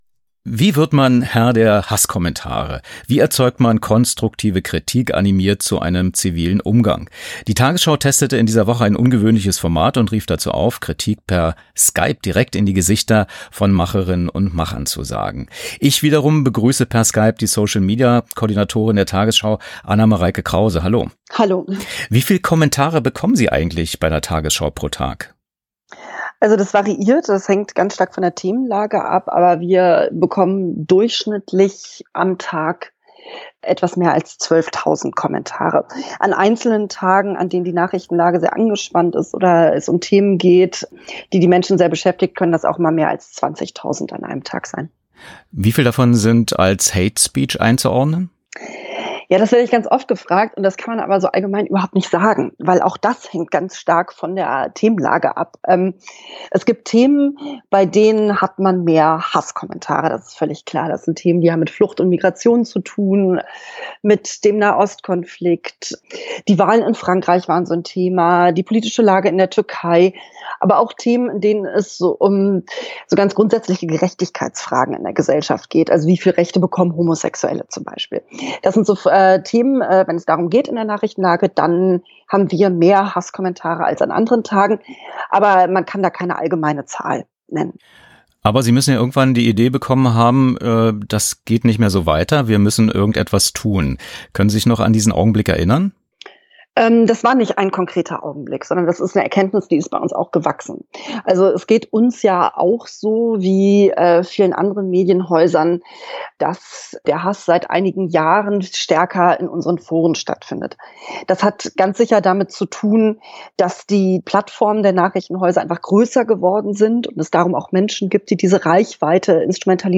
Was: Skype-Interview zur Aktion: „Sag’s mir ins Gesicht“